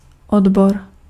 Ääntäminen
IPA: /sɛk.sjɔ̃/